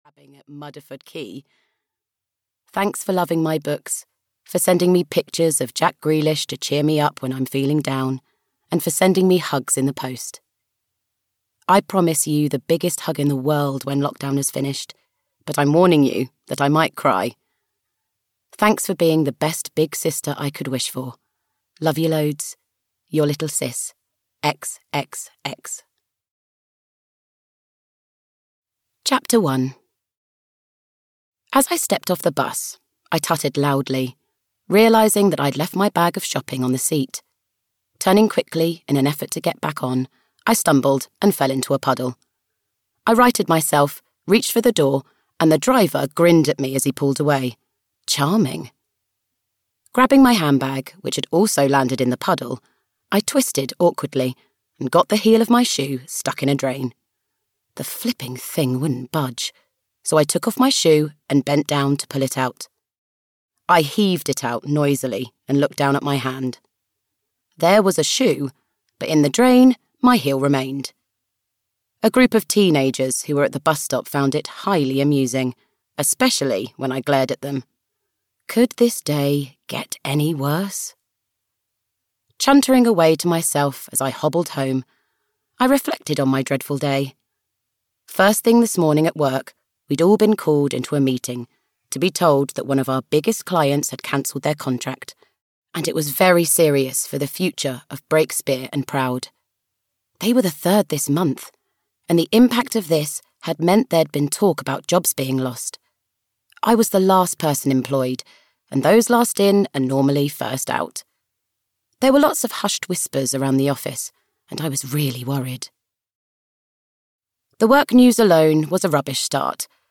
Moonlight Over Muddleford Cove (EN) audiokniha
Ukázka z knihy